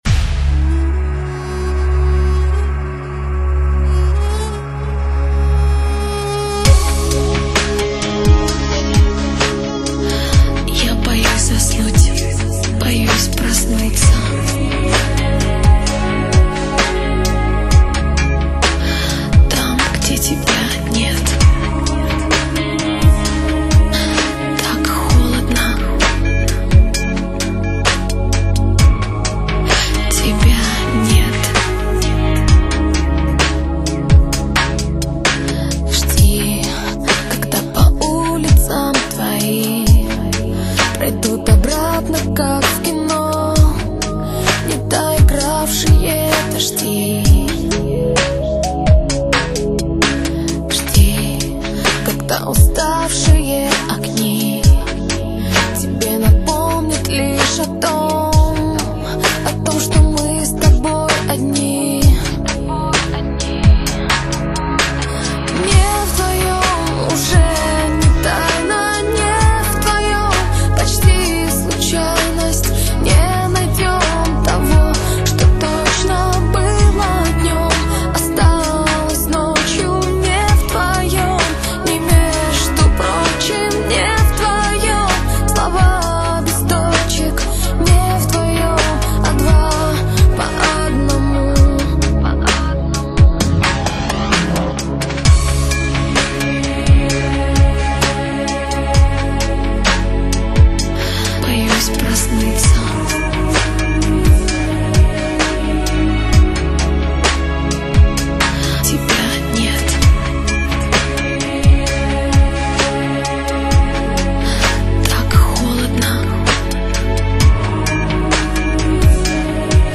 [3/7/2010][转帖]忧伤、悲凉、沙哑、空灵的女声